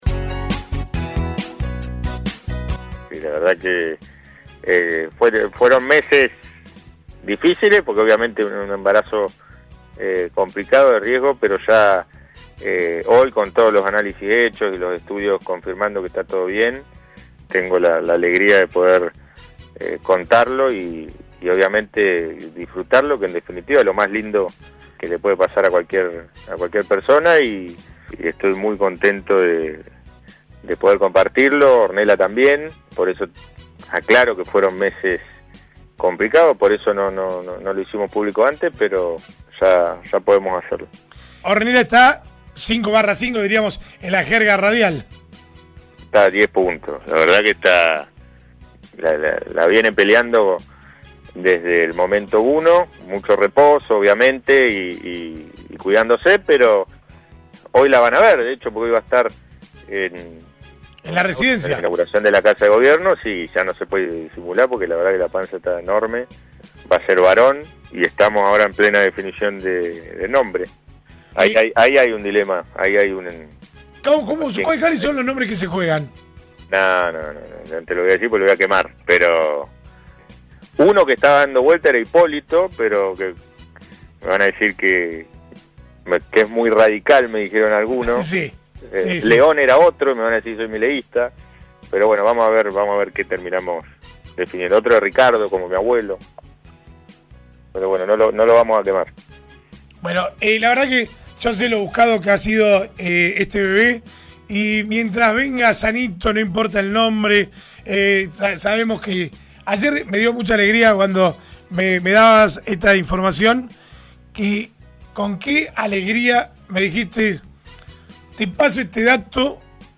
Emocionado, así lo comentó el gobernador en el aire de LA MAÑANA DE HOY: